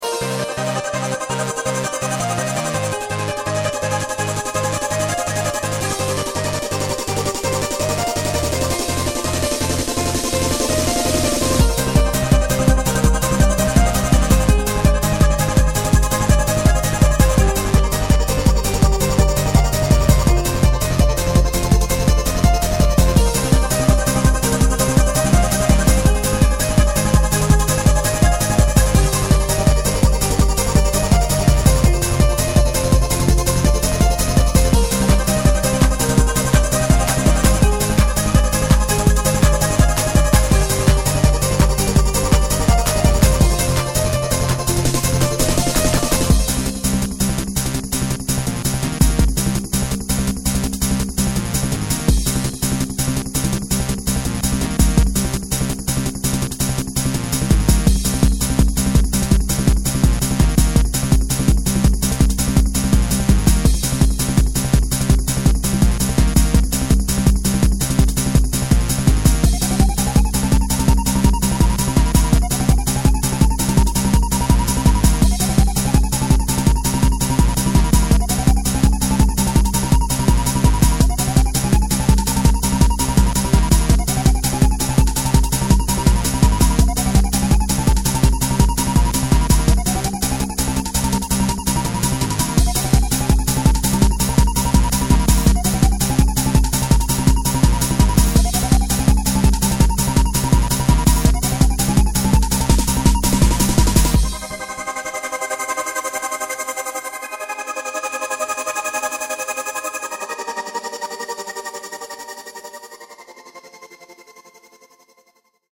Hard-Trance